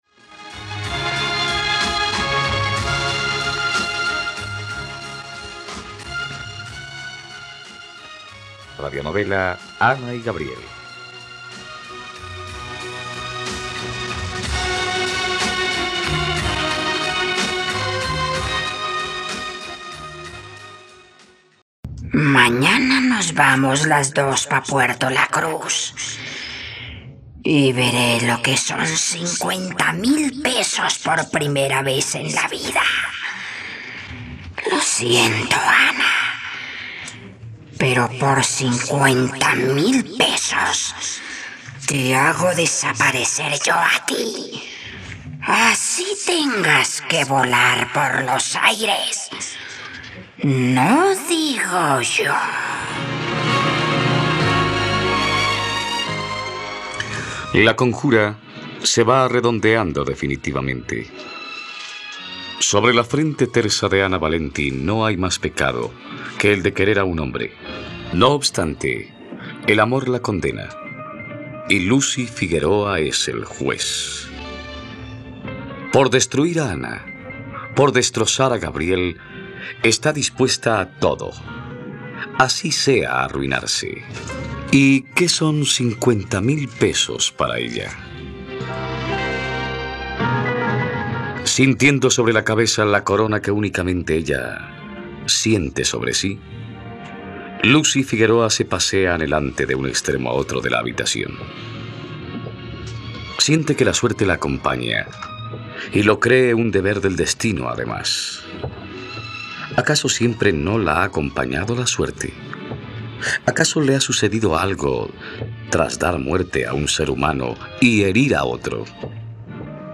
..Radionovela. Escucha ahora el capítulo 111 de la historia de amor de Ana y Gabriel en la plataforma de streaming de los colombianos: RTVCPlay.